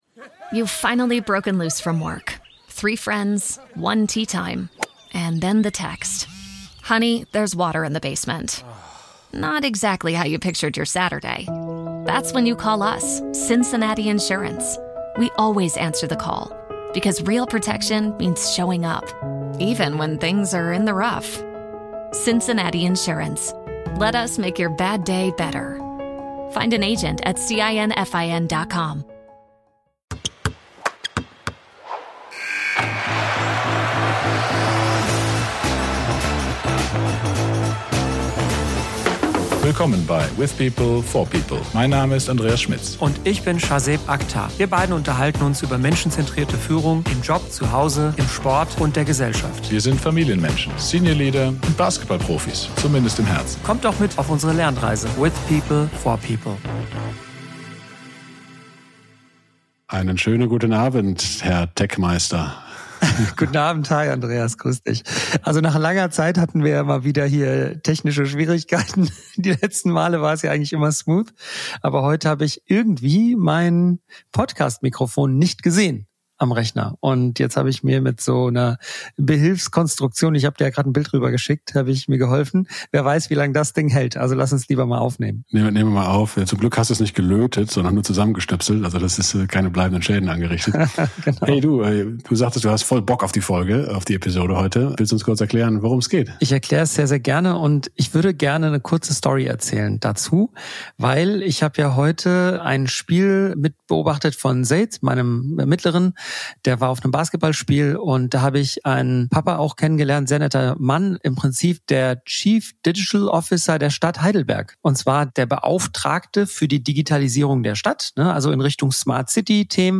in einer heiteren Stimmung